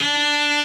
b_cello1_v100l2o5d.ogg